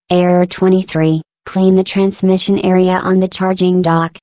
glados_dreame_voice_pack_customized